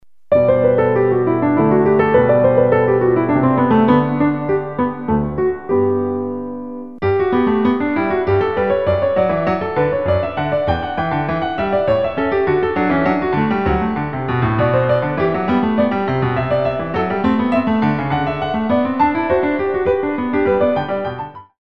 Cloches